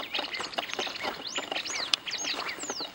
Alegrinho-balança-rabo (Stigmatura budytoides)
Nome em Inglês: Greater Wagtail-Tyrant
Localidade ou área protegida: Amaicha del Valle
Condição: Selvagem
Certeza: Gravado Vocal